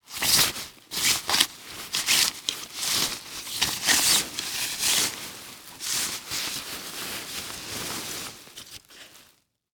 household
Metal Stake Sliding into Tent